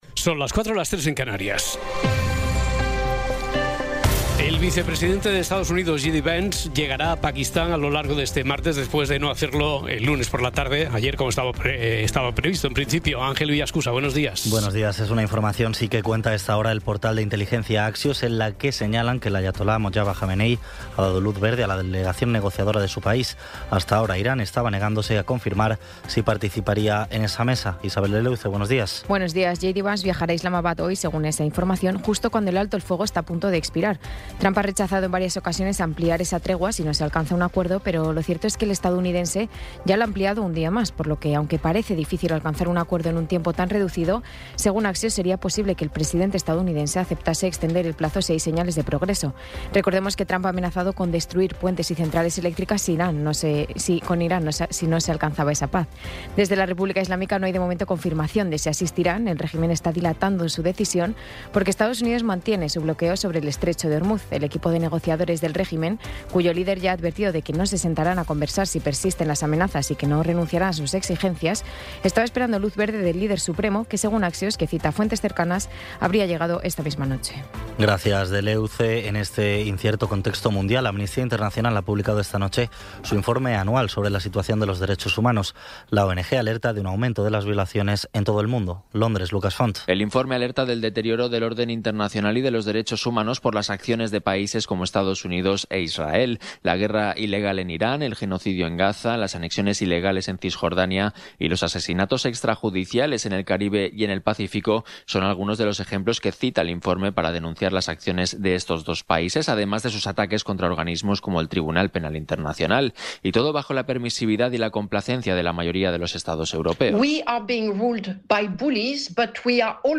Resumen informativo con las noticias más destacadas del 21 de abril de 2026 a las cuatro de la mañana.